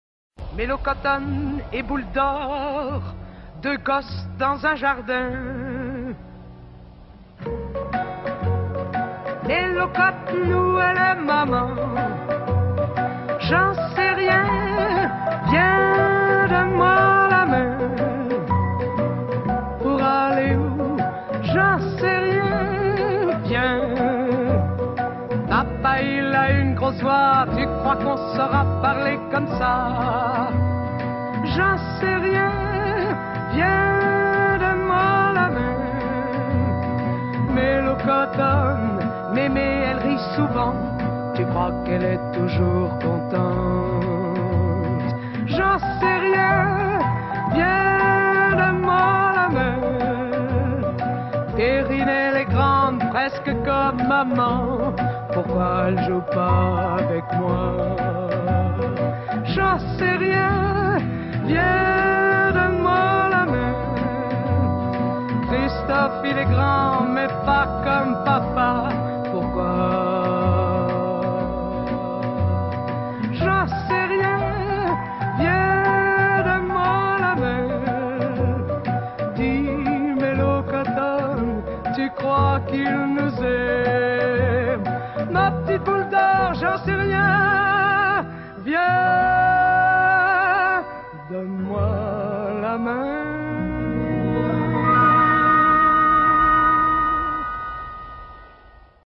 仏蘭西で、1958年ごろからギター１本のブルーズ歌手